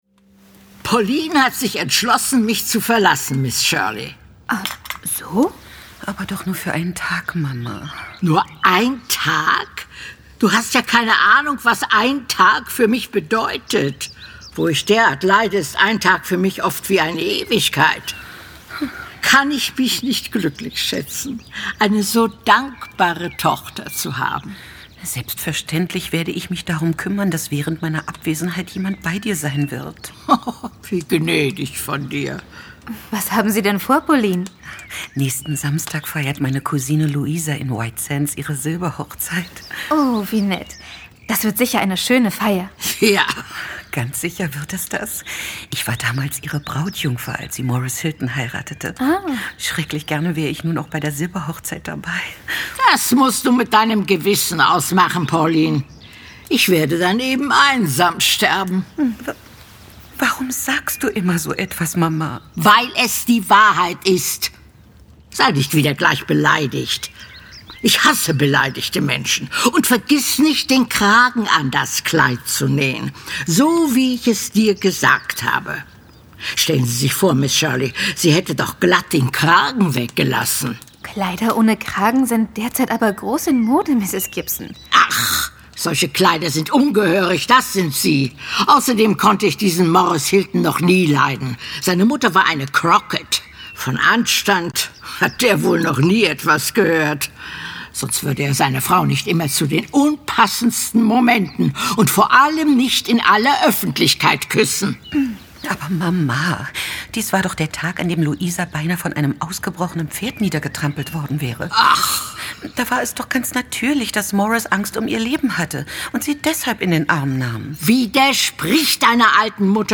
Anne in Windy Poplars - Folge 14 Ein harter Brocken. L.M. Montgomery (Autor) Marie Bierstedt (Sprecher) Audio-CD 2009 | 7.